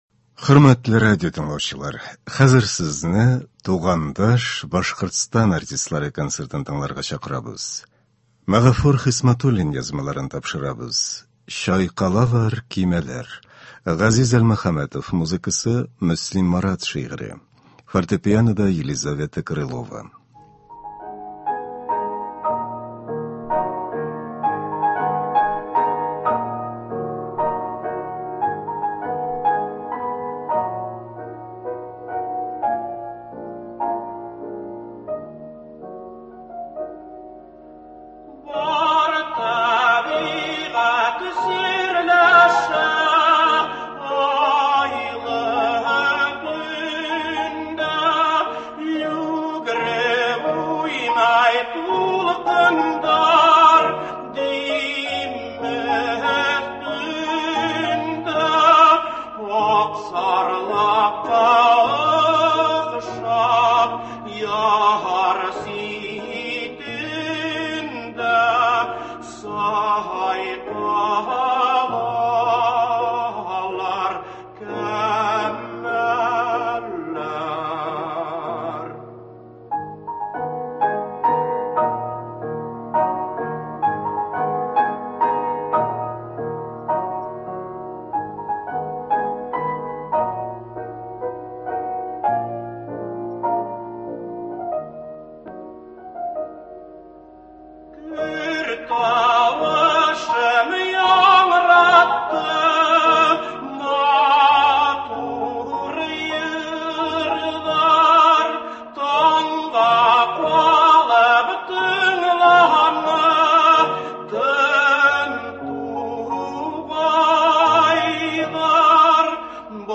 Көндезге концерт.
Башкортостан артистлары концерты.